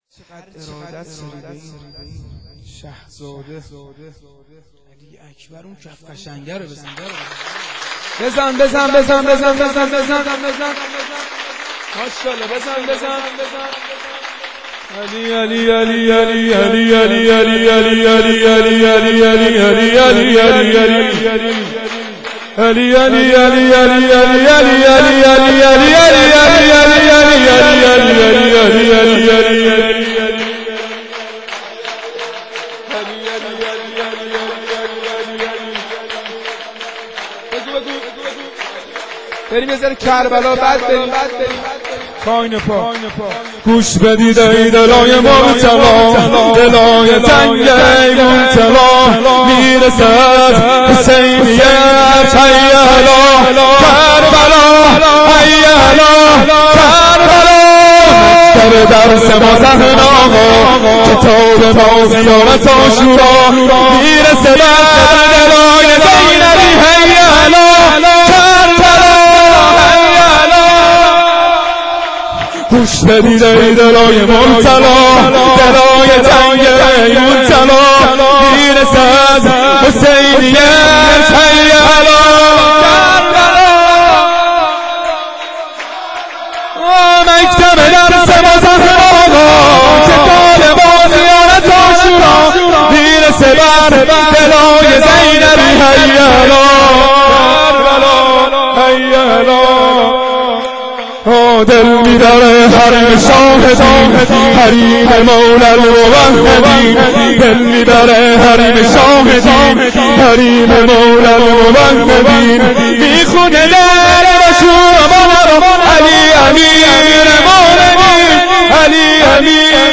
سرود
شام میلاد حضرت علی اکبر 1392